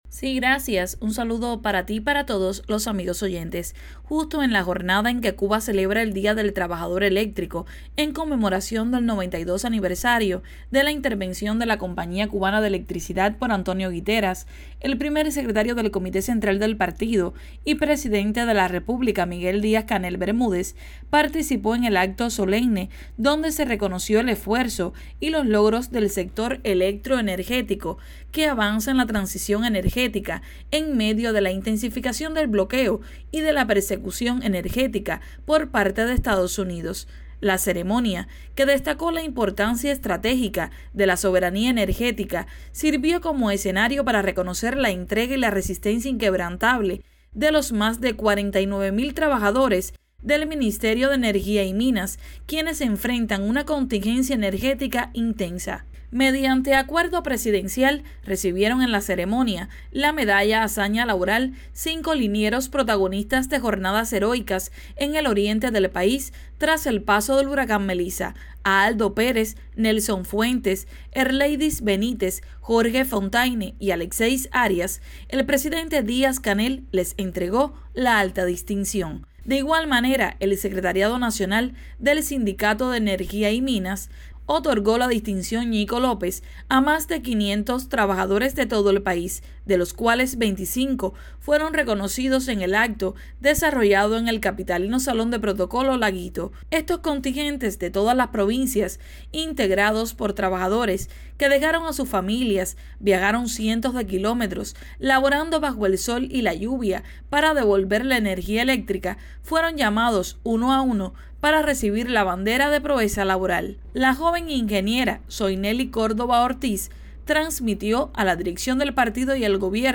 Con la presencia del Primer Secretario del Comité Central del Partido Comunista de Cuba y Presidente de la República, Miguel Díaz-Canel Bermúdez, tuvo lugar en la tarde de este miércoles el Acto por el Día del Trabajador del sector eléctrico, donde fueron reconocidos los sobrados méritos de artífices de ese ámbito tan vital y que resiste los embates de un bloqueo feroz.